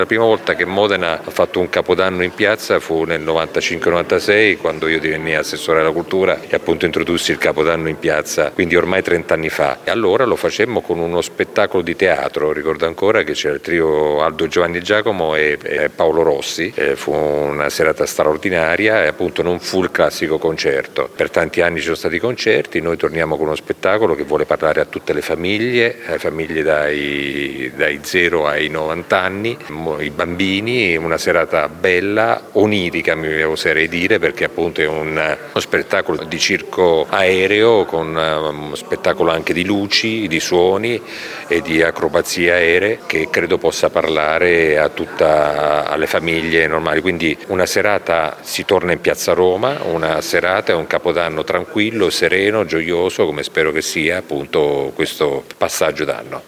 Il sindaco Massimo Mezzetti: